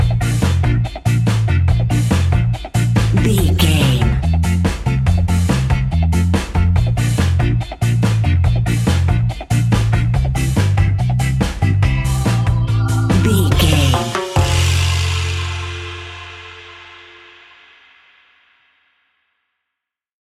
Ionian/Major
laid back
off beat
drums
skank guitar
hammond organ
horns